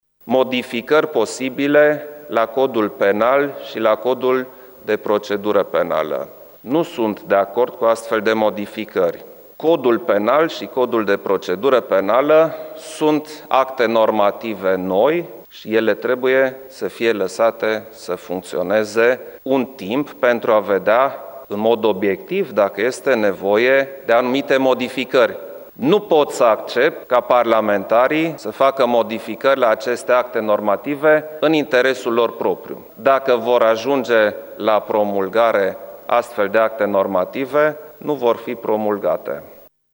Preşedintele Klaus Iohannis a anunţat, astăzi, într-o conferinţă de presă, că nu va promulga proiectele legislative de modificare a Codurilor Penal şi de Procedură Penală recent adoptate de parlament.
Klaus Iohannis: